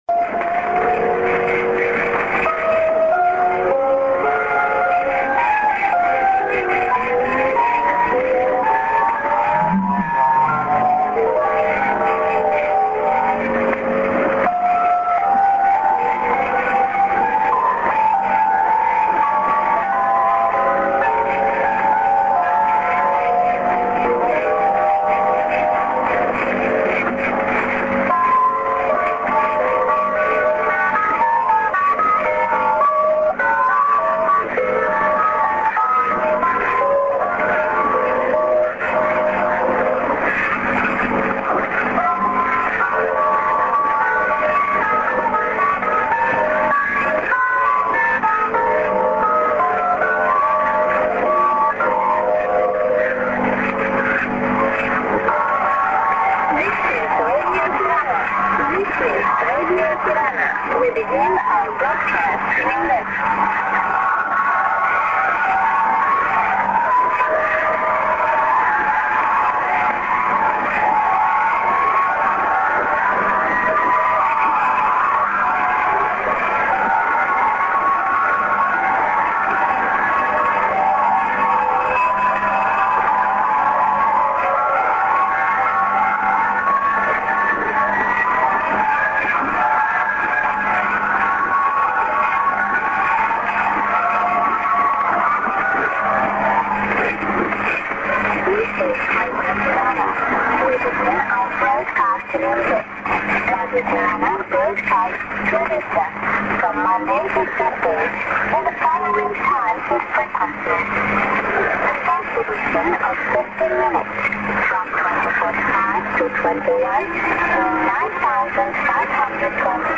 St. IS->ID(women)->ID+SKJ(women)->